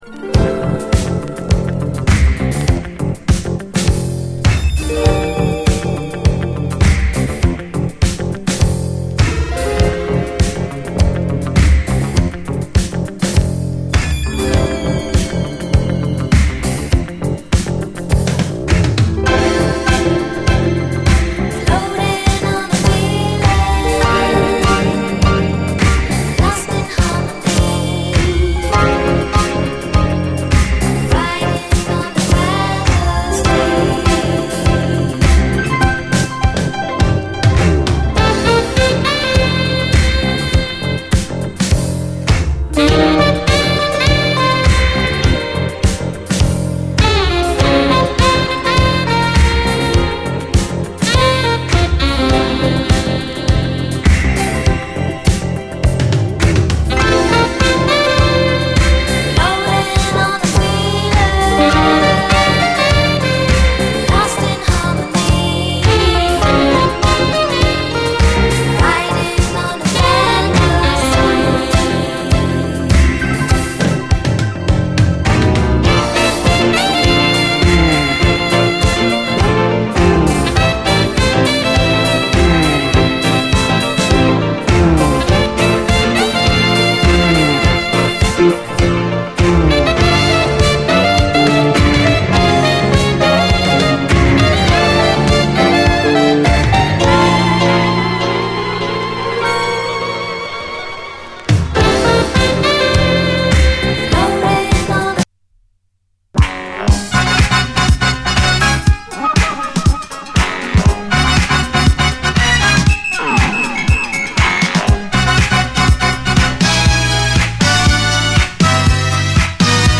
SOUL, 70's～ SOUL, DISCO, 7INCH
お馴染みのロマンティックなムードに浸るメロウ・フローター！